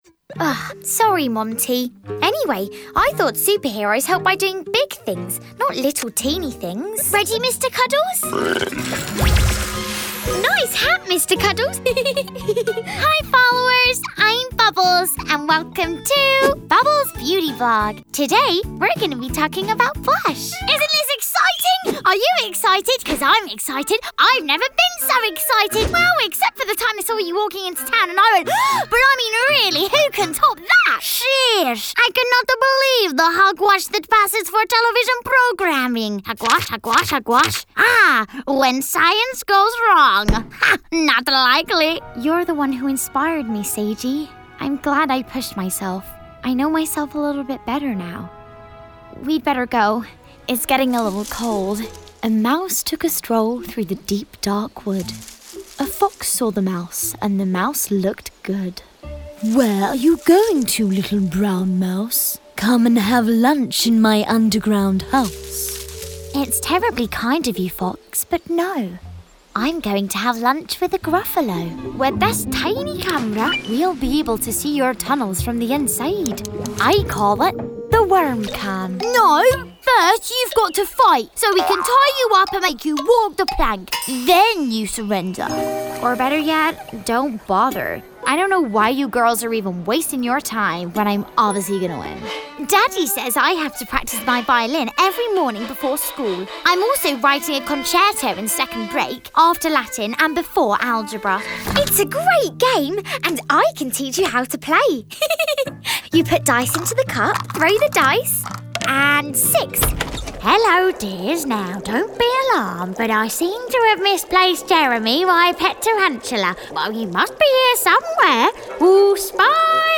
Good versatile accents.
• Female
• Essex
• Estuary English
• Standard English R P
• Standard U S